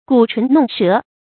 鼓唇弄舌 注音： ㄍㄨˇ ㄔㄨㄣˊ ㄋㄨㄙˋ ㄕㄜˊ 讀音讀法： 意思解釋： 鼓：撥動，振動。